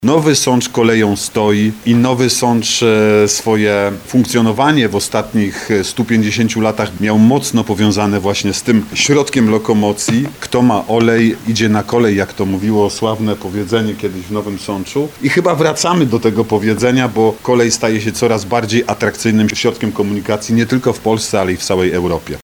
Chyba do tego powiedzenia wracamy, bo kolej staje się coraz bardziej atrakcyjnym środkiem komunikacji nie tylko w Polsce, ale i w Europie – mówi zastępca prezydenta Nowego Sącza Artur Bochenek.